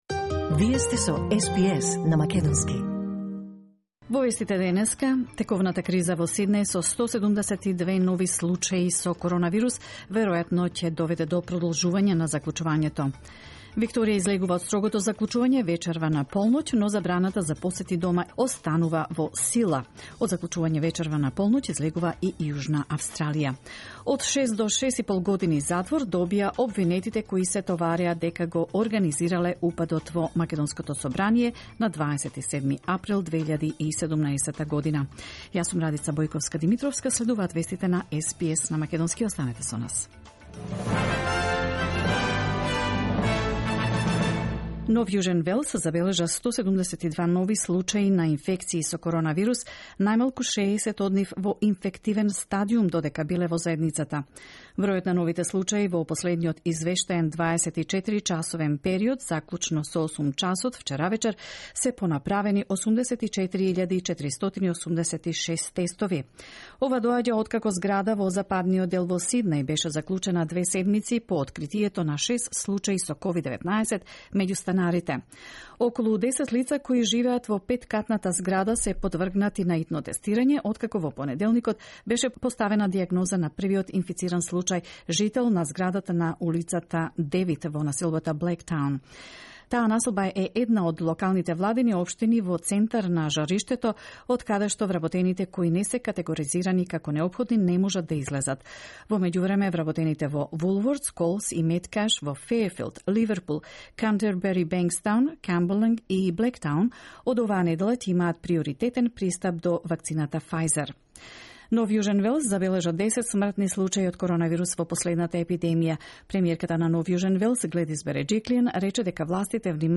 SBS News in Macedonian 27July 2021